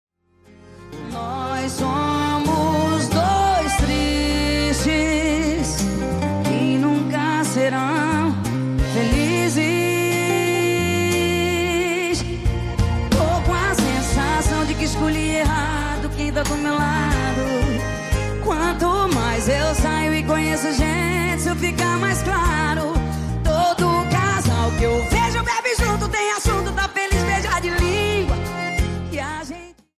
Brasileira